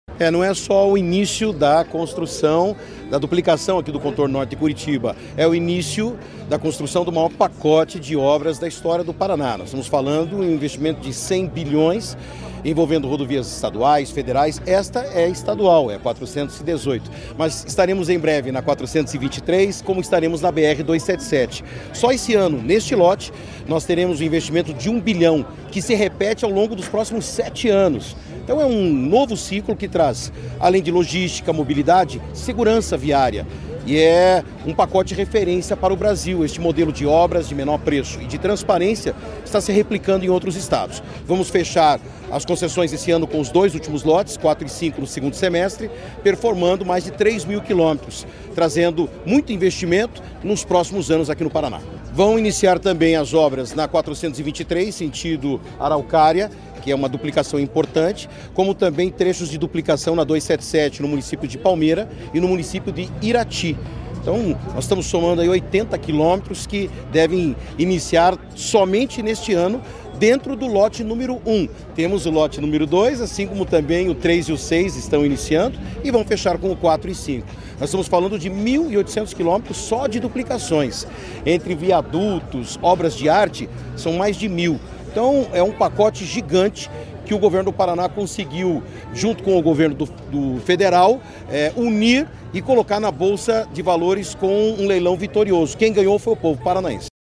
Sonora do secretário de Infraestrutura e Logística, Sandro Alex, sobre o início da duplicação do Contorno Norte de Curitiba